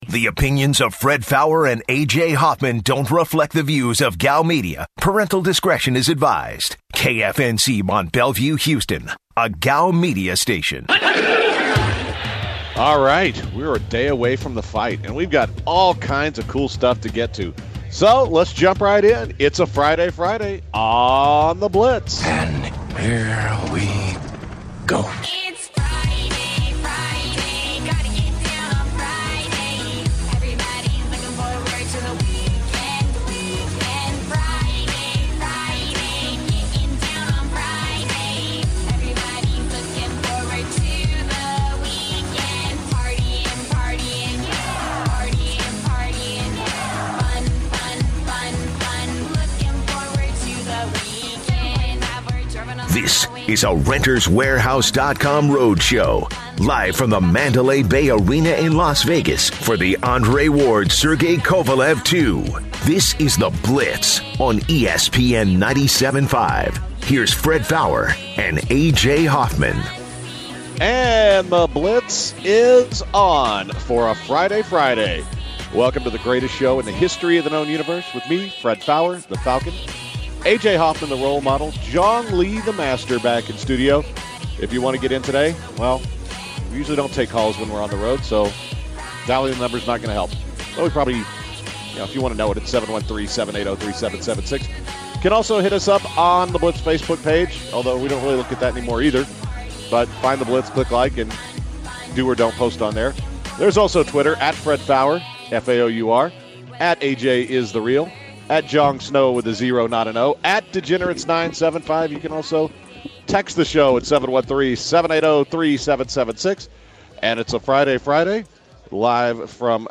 The guys are joing by guest Jim Lampley who weighs in on Ward vs. Koralev, and Mayweather vs. McGregor. The guys also share an interesting news story about a botched ball surgery.